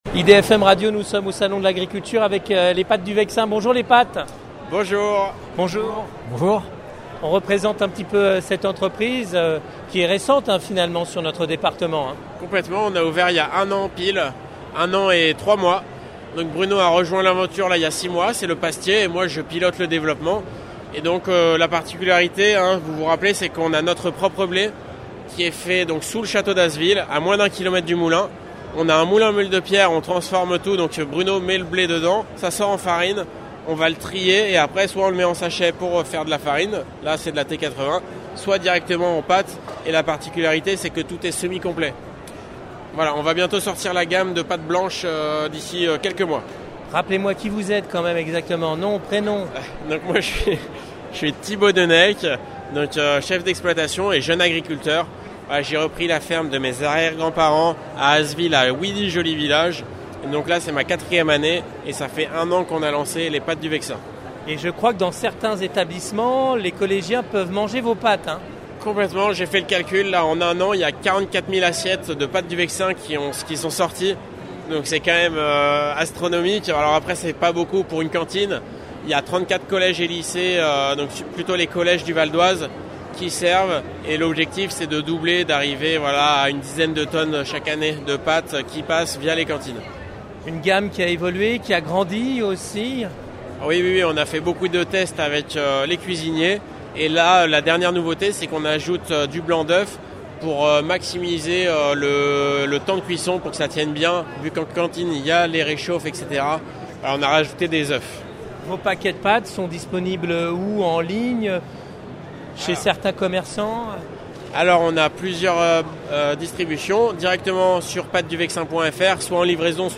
Immersion au cœur du salon avec les producteurs du V.O
Itw-SALON-BON-AGRIC.mp3